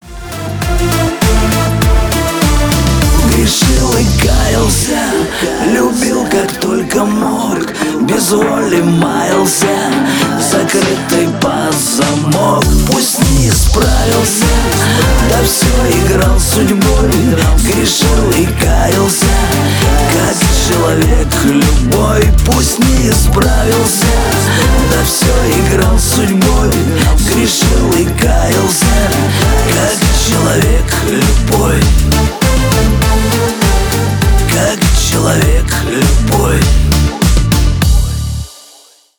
• Качество: 320 kbps, Stereo
Шансон